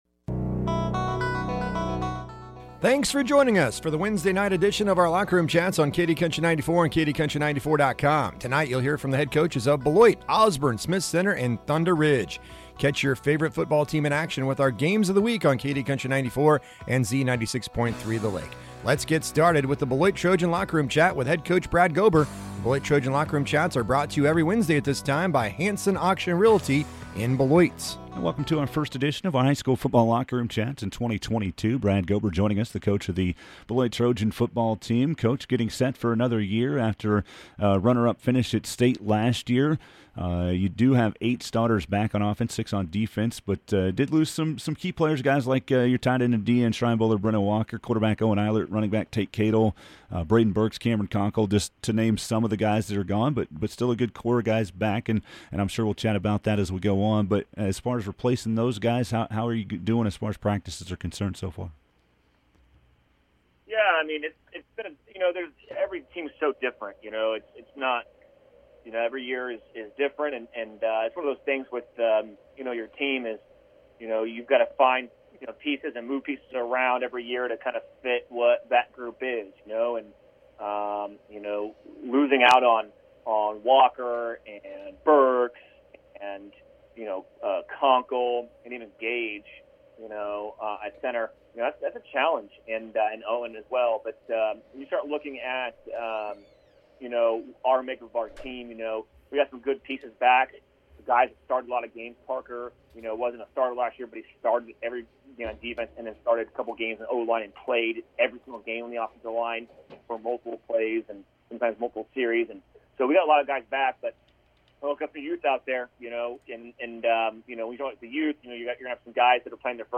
is joined by the head football coaches